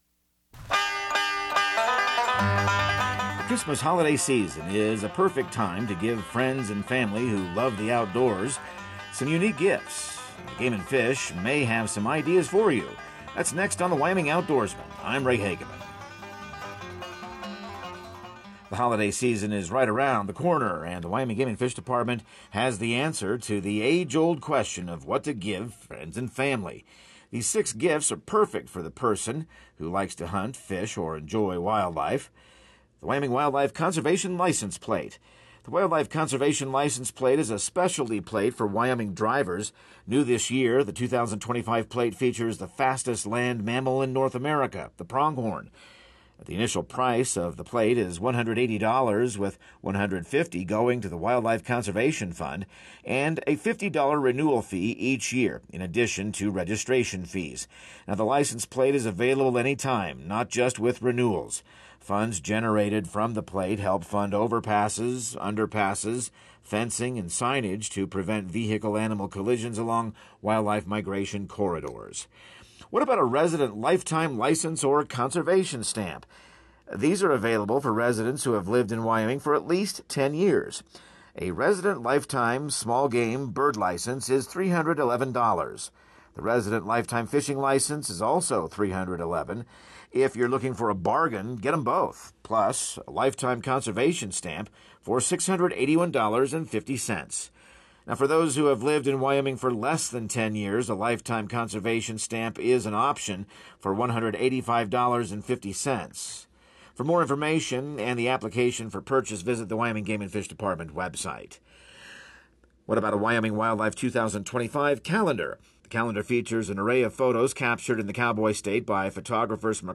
Radio news | Week of December 9